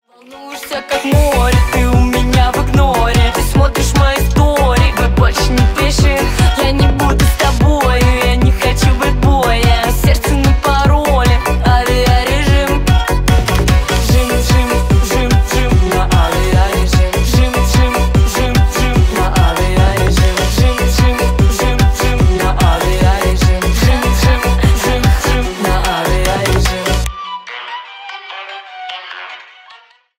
Поп Музыка
весёлые